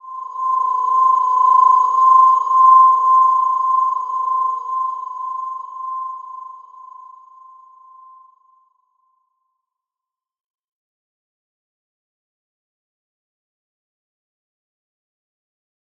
Slow-Distant-Chime-C6-mf.wav